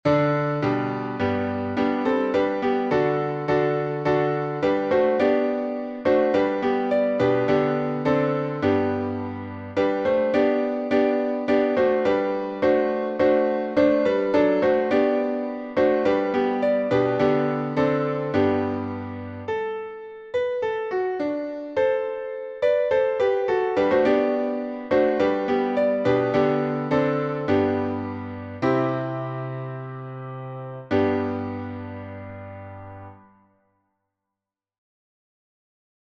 Key signature: G major (1 sharp) Time signature: 4/4 Meter: 8.8.8.8. with Refrain